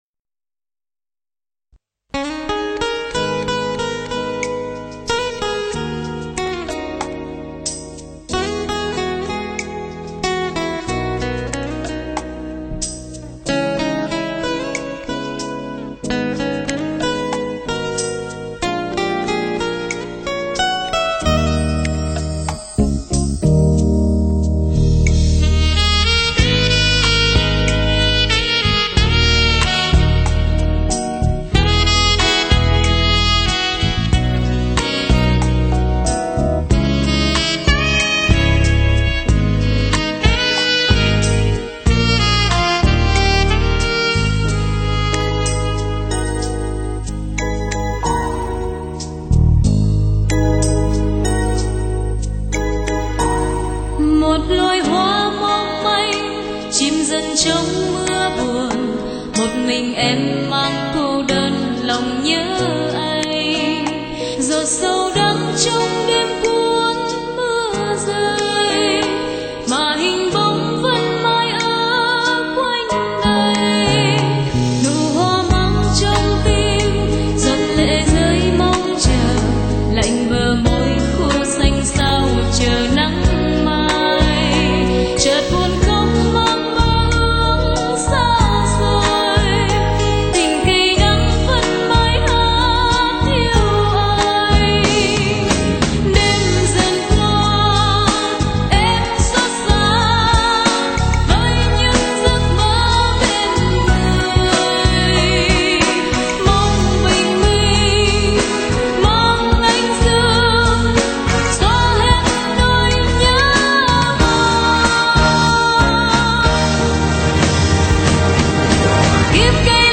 Nhạc Hoa.